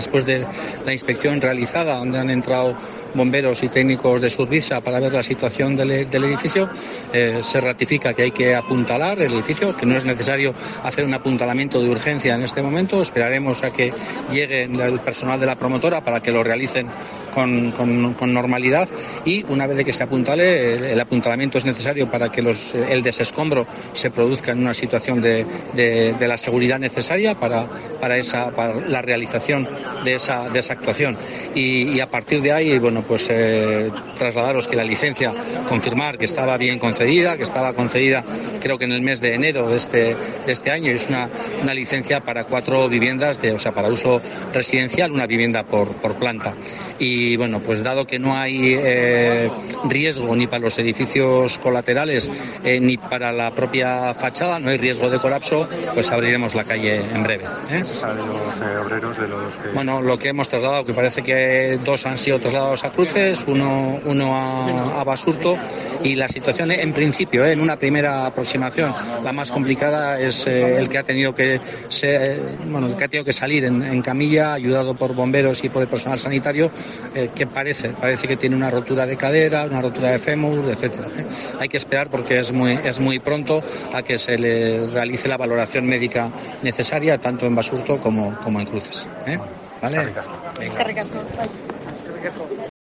Juan Mari Aburto, alcalde de Bilbao, informa sobre el derrumbe de un edificio